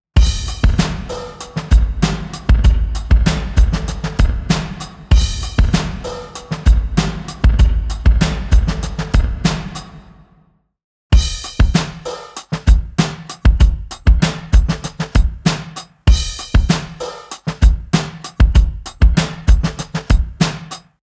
UltraReverb | Drums | Preset: Plain Hall
Drums-Plain-Hall.mp3